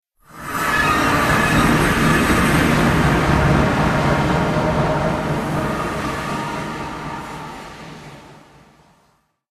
Оригинальные игровые эффекты помогут добавить атмосферу Майнкрафта в ваши видео.
Minecraft — загадочные звуки портала